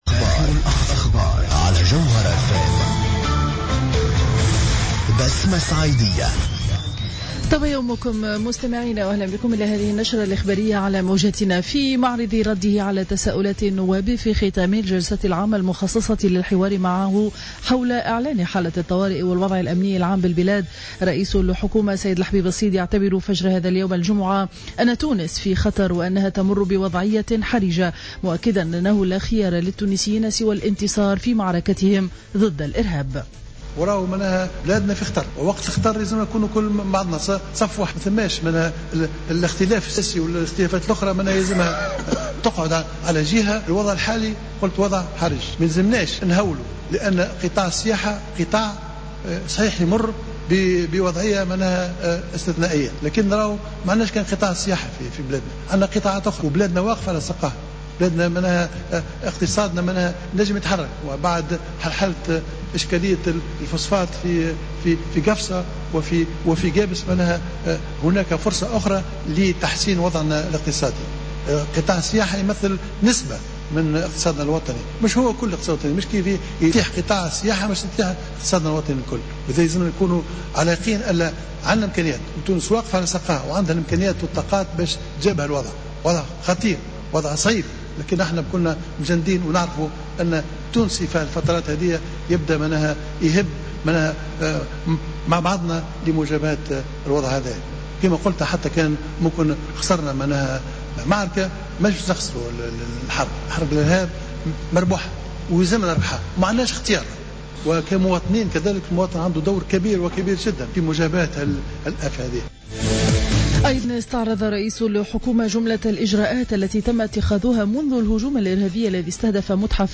نشرة أخبار السابعة صباحا ليوم الجمعة 10 جويلية 2015